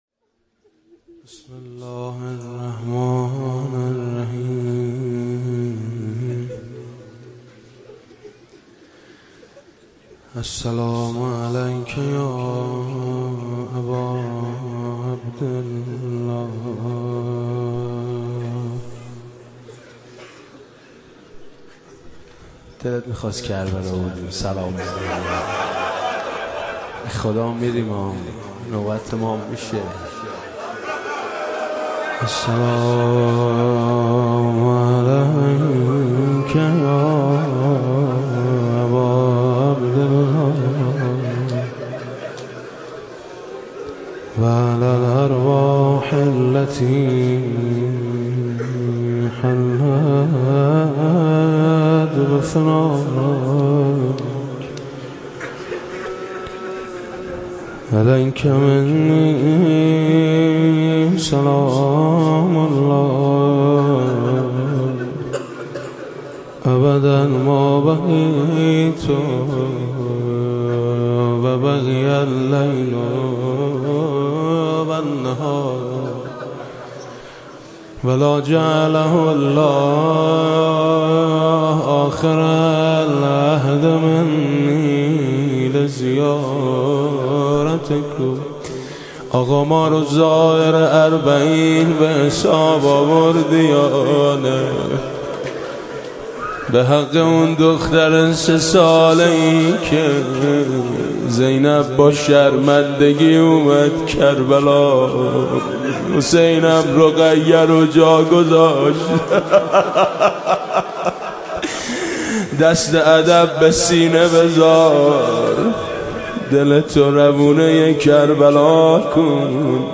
مداحی اربعین
امامزاده قاضی الصابر (ع)